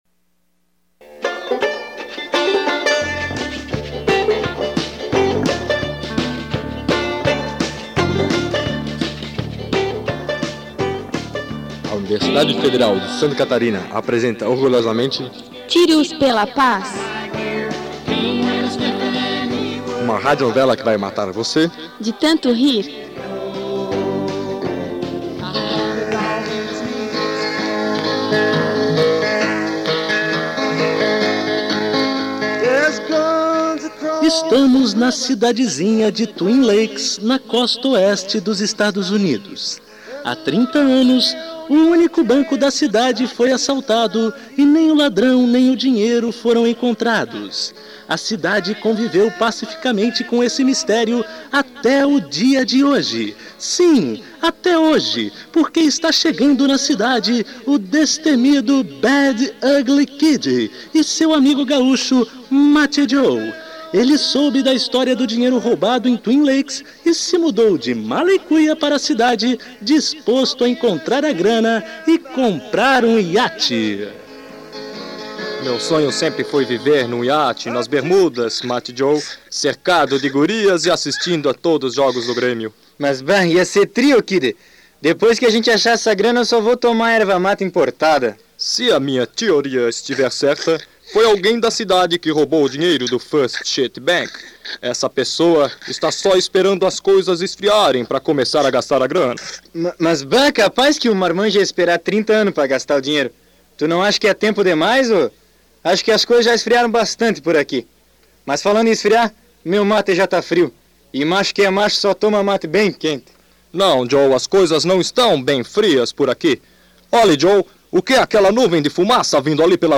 Abstract: Uma novela que vai matar você...de rir.